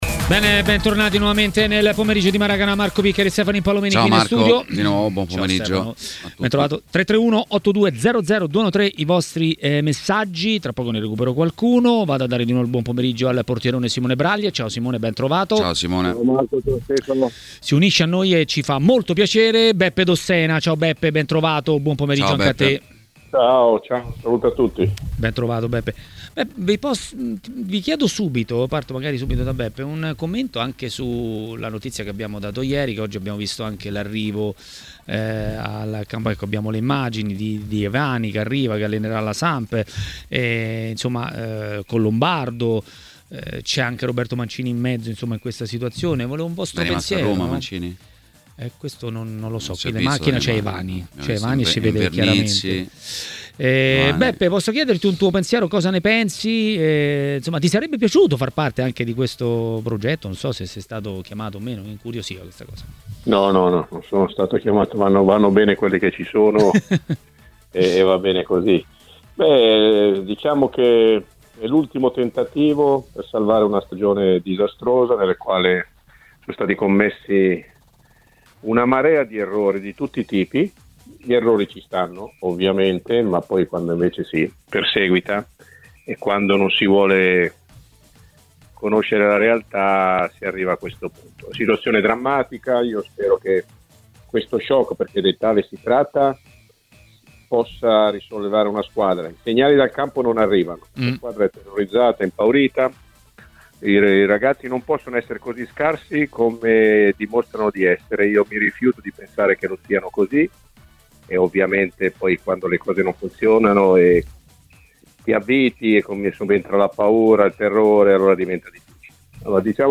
L'ex calciatore Beppe Dossena a Maracanà, nel pomeriggio di TMW Radio, ha parlato di Sampdoria e non solo.